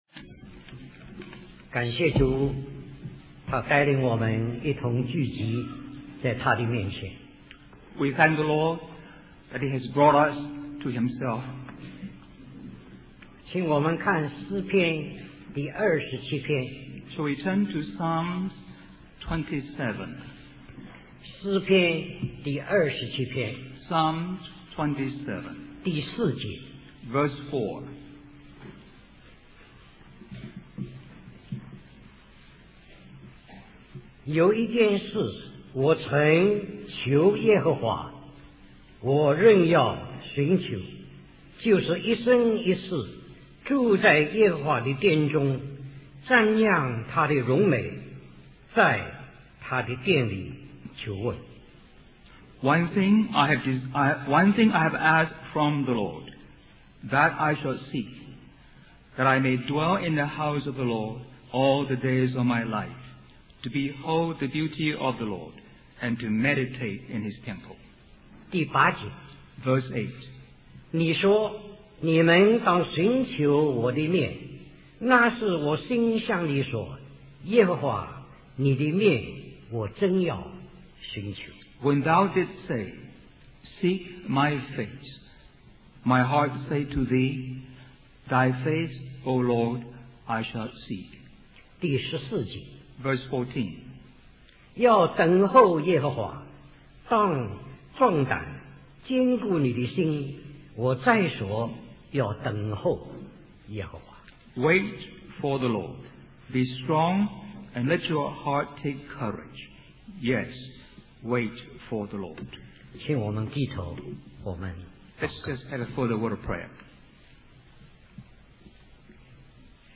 Special Conference For Service, Hong Kong